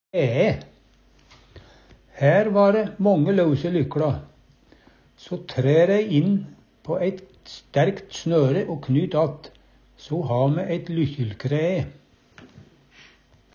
kræe - Numedalsmål (en-US)